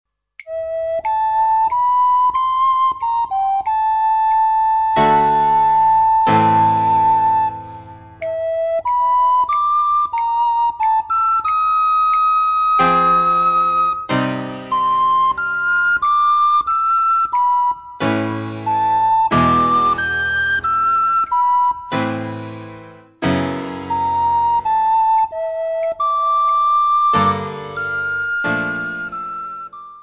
für Sopranblockflöte und Klavier
Besetzung: Sopranblockflöte und Klavier
Klassisches Blockflötenrepertoire auf Noten mit Playback-CD.